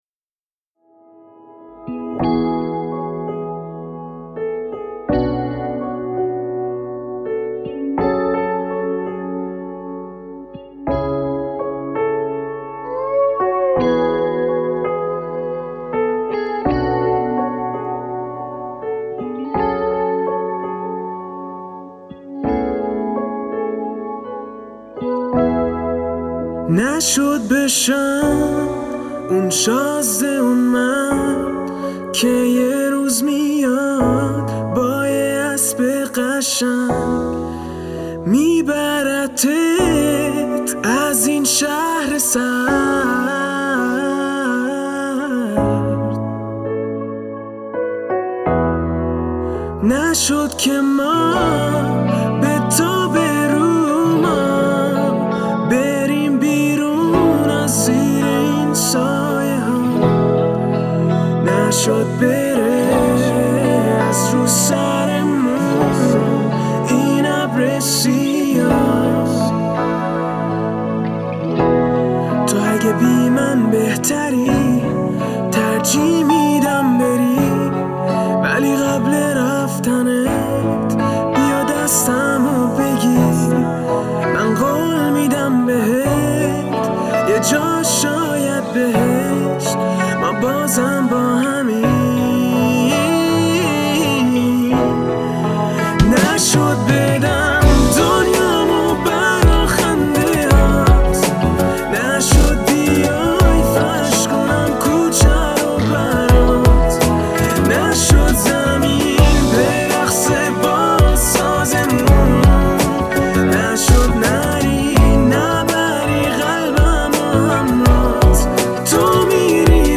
آهنگ غمگین
پاپ pop